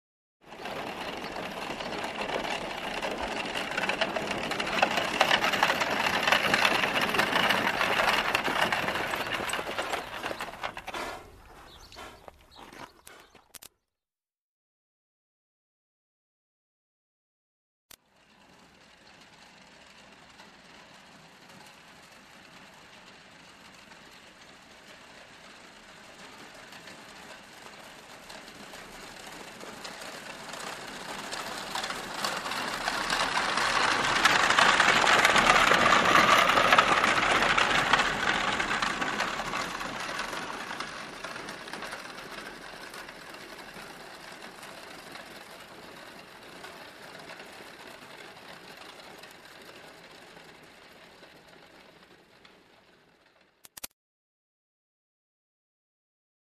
кони звуки скачать, слушать онлайн ✔в хорошем качестве